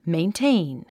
発音
meintéin　メインテイン